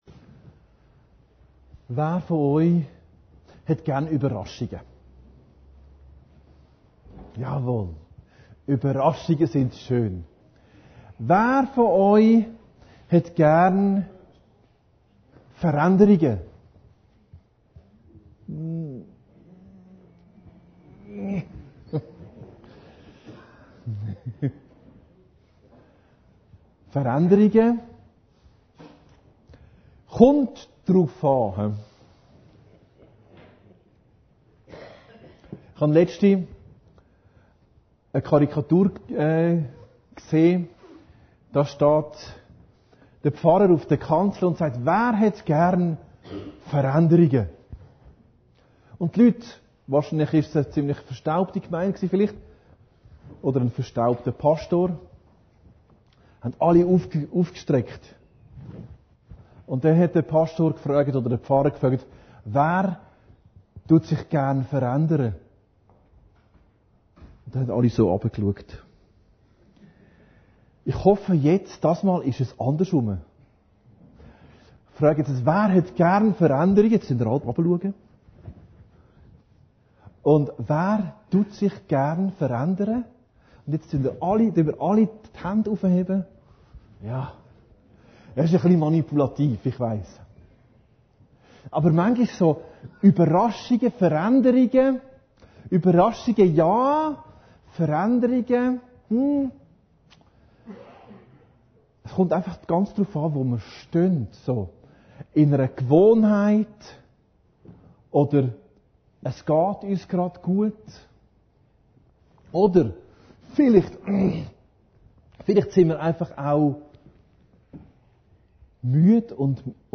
Predigten Heilsarmee Aargau Süd – Die Fusswaschung